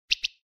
Птичка твиттер